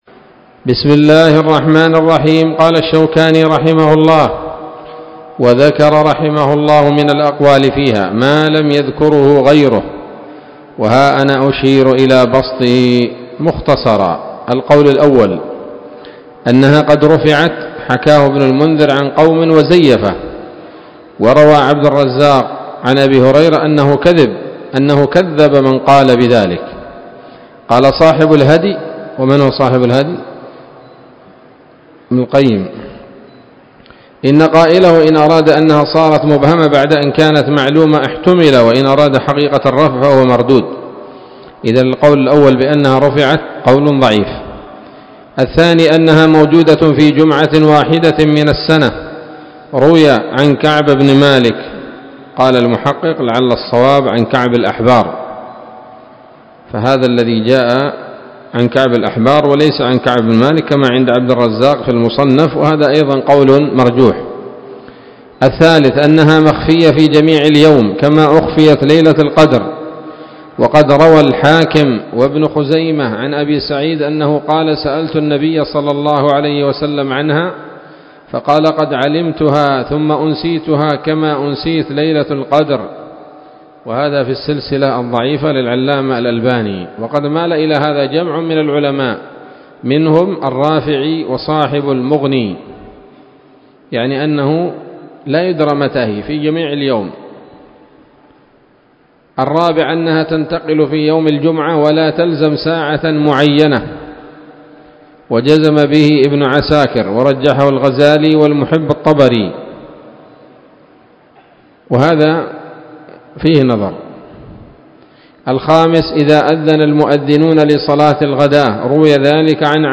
الدرس الثاني عشر من ‌‌‌‌أَبْوَاب الجمعة من نيل الأوطار